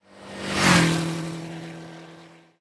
car9.ogg